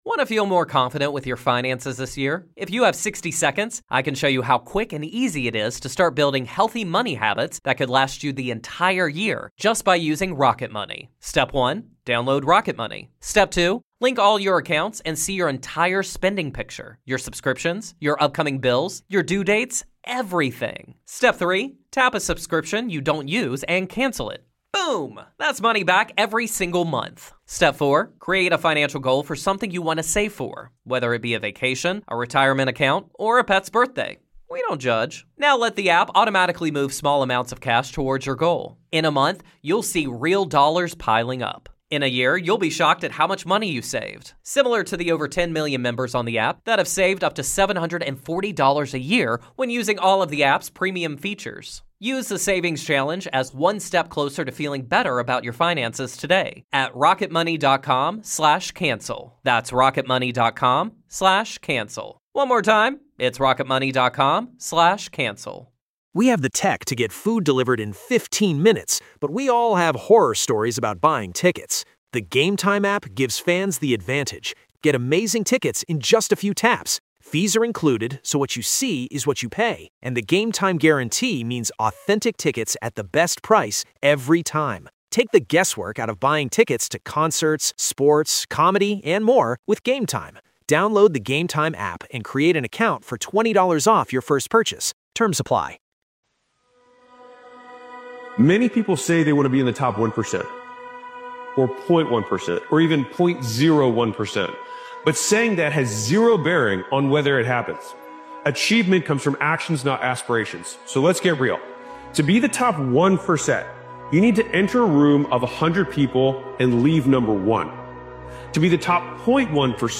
This powerful motivational speeches compilation is a declaration of vision and belief - the mindset of someone who refuses to settle for anything less than greatness. Champions are built through persistence, hunger, and the daily decision to keep going when others stop.